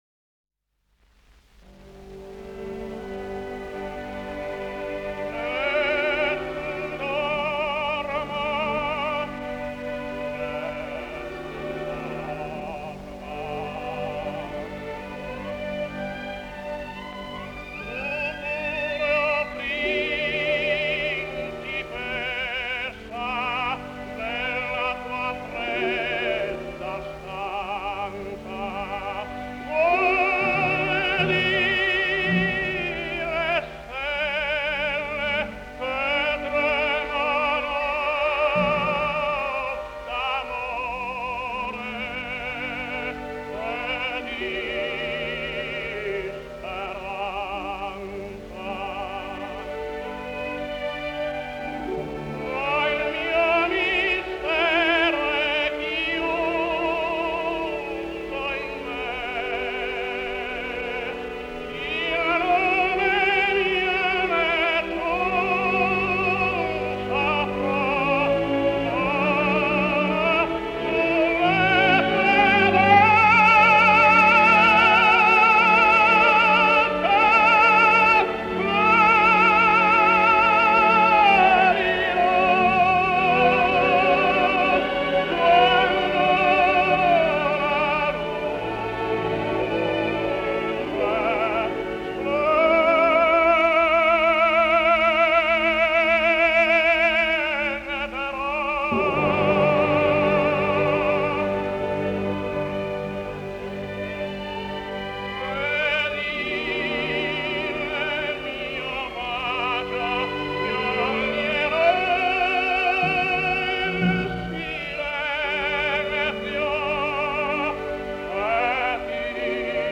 Italian Tenor.